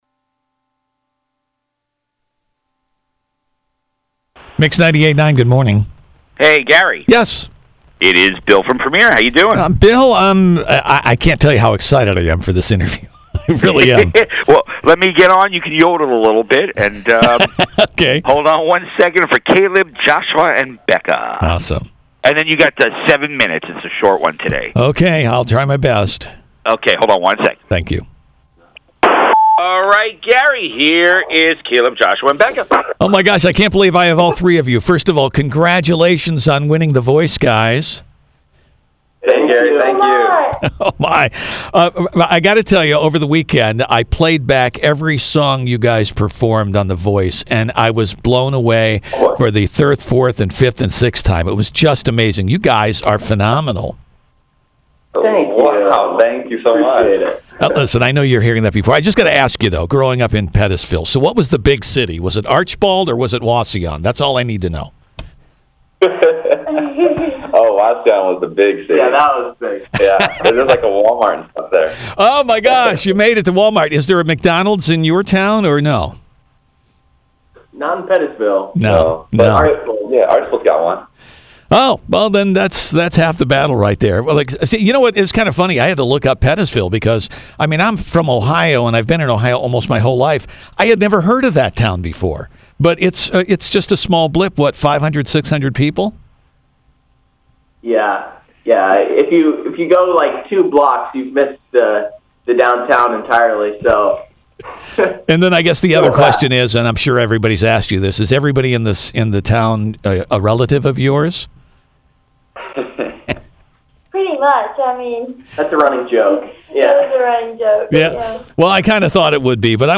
We think you will love their humble attitude in this inspiring interview https